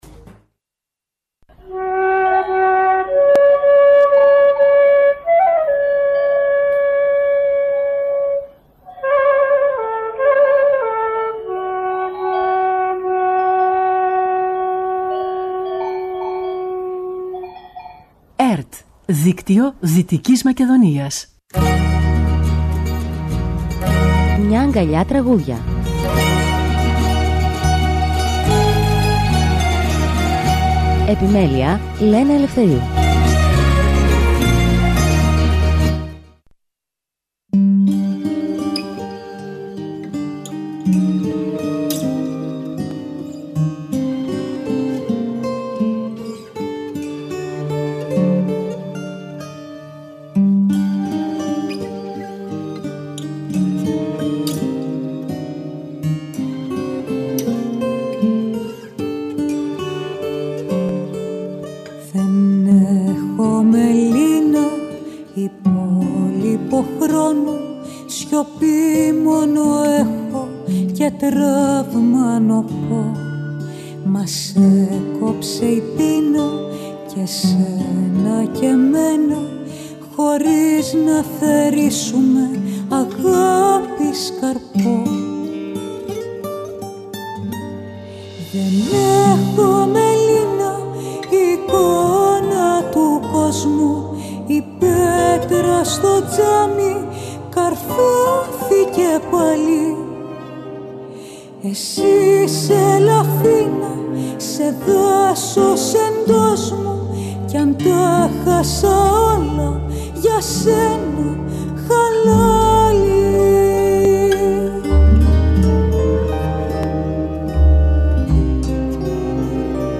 Μουσική εκπομπή που παρουσιάζει νέες δισκογραφικές δουλειές, βιβλιοπαρουσιάσεις και καλλιτεχνικές εκδηλώσεις.
ΣΥΝΕΝΤΕΥΞΗ